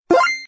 Download 8 bit sound effect for free.
8 Bit